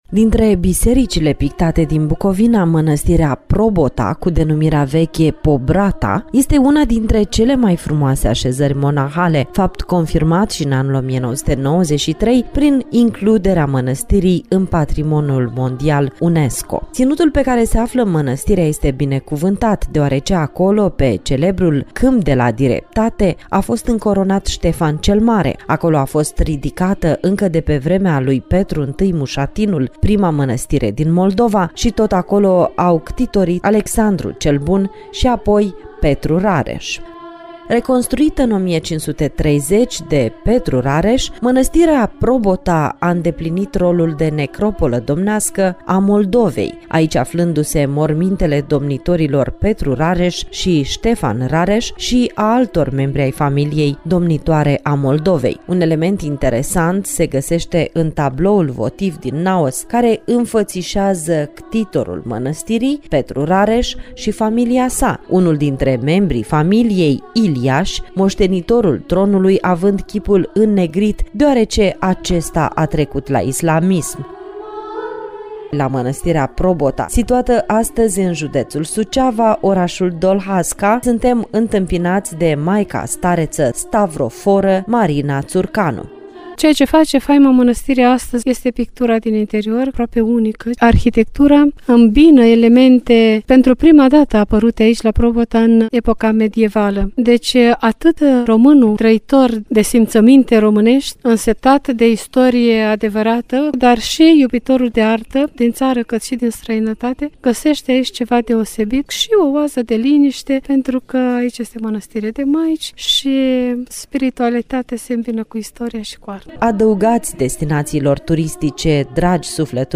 Manastirea-Probota-jud-Suceava-Eu-aleg-Romania.mp3